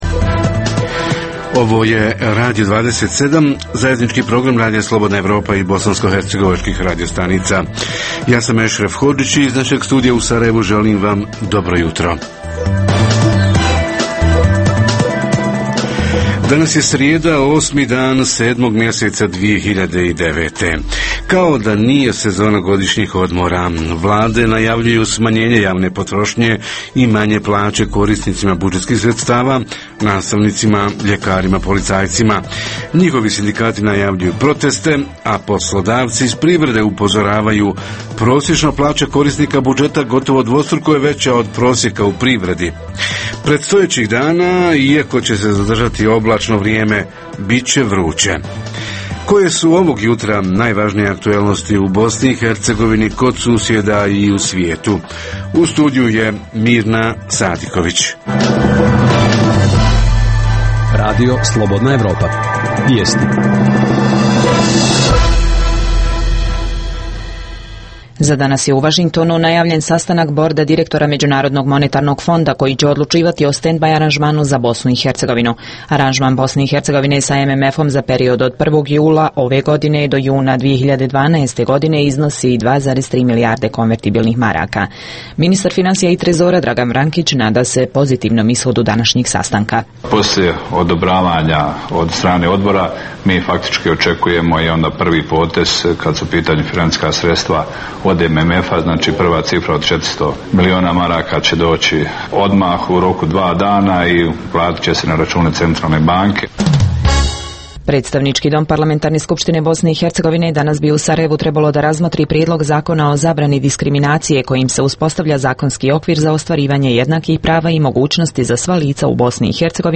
Jutarnji program za BiH koji se emituje uživo pita: godišnji odmori, putovanja i zdravstveno osiguranje – ko i kako plaća zdravstvenu uslugu?